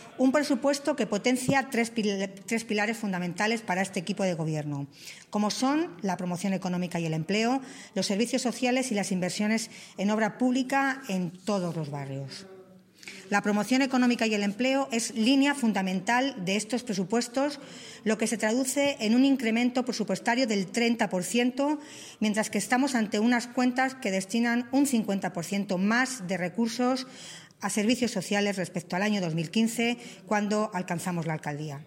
AUDIOS. Milagros Tolón, alcaldesa de Toledo
milagros-tolon_pilares-del-presupuesto_promocion-economica-y-empleo_servicios-sociales_obra-publica.mp3